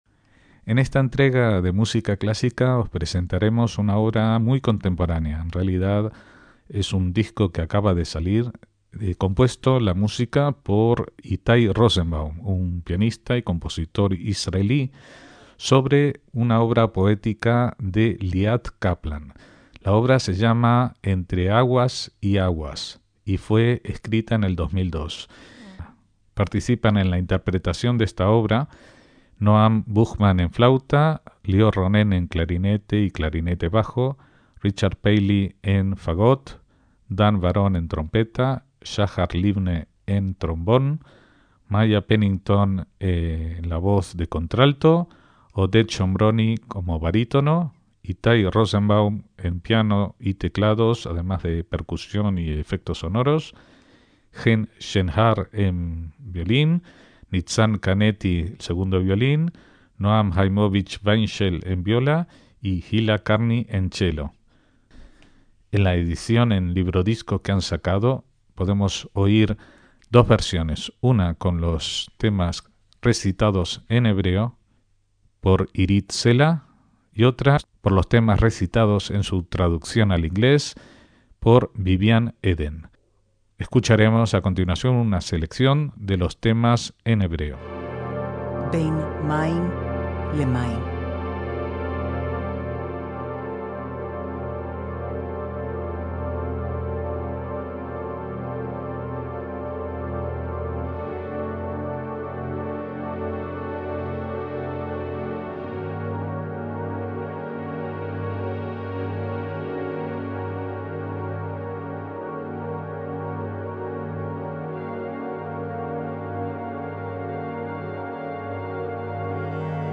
MÚSICA CLÁSICA
obra de tinte atonal contemporáneo
suite para ensemble variante y narrador
flauta
clarinete y clarinete bajo
fagot
trompeta
trombón
contralto